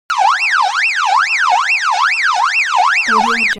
دانلود افکت صدای آژیر پلیس از نزدیک
افکت صدای آژیر پلیس کارتونی یک گزینه عالی برای هر پروژه ای است که به صداهای شهری و جنبه های دیگر مانند پلیس، آژیر و زنگ هشدار نیاز دارد.
Sample rate 16-Bit Stereo, 44.1 kHz
Looped No